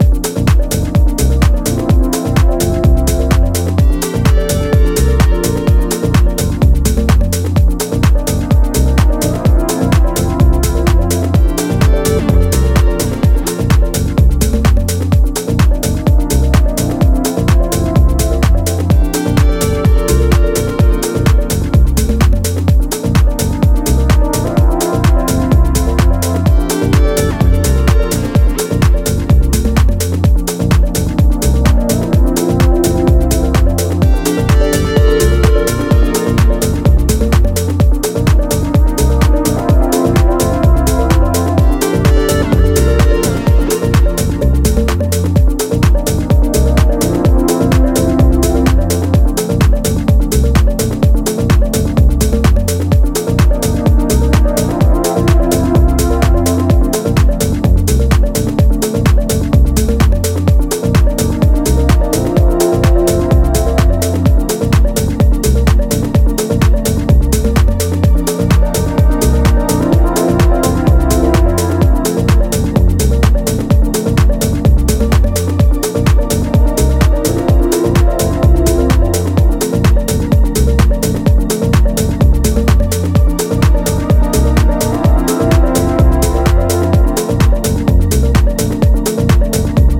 ジャンル(スタイル) DEEP HOUSE / HOUSE / TECH HOUSE